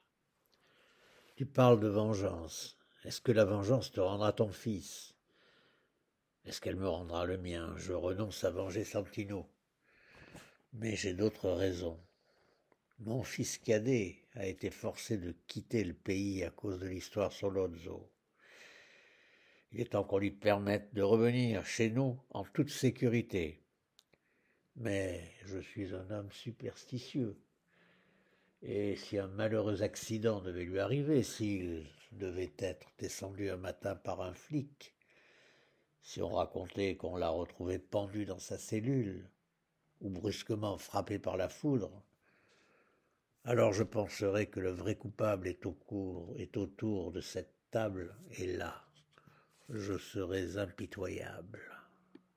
Voix off
- Ténor